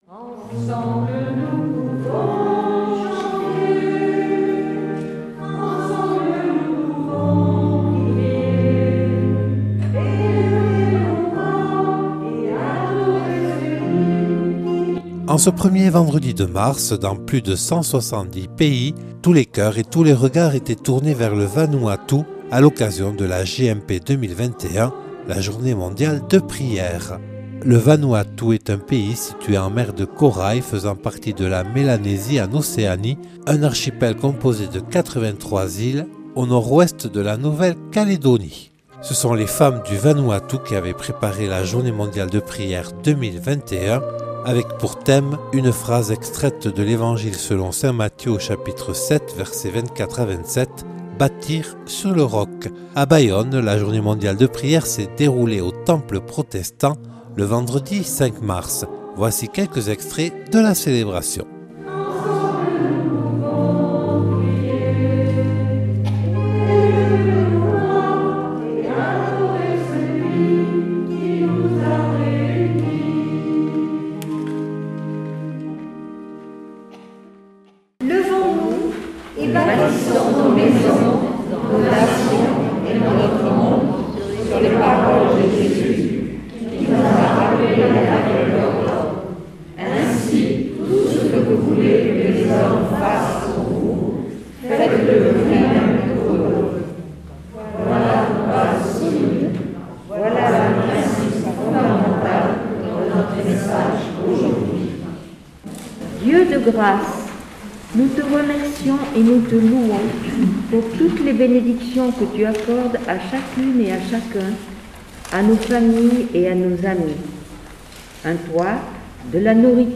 Retour sur la Journée Mondiale de Prière (JMP) le vendredi 5 mars 2021 au Temple de Bayonne.
Interviews et reportages